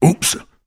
На этой странице собрана коллекция звуков, сопровождающих мелкие проблемы и досадные недоразумения.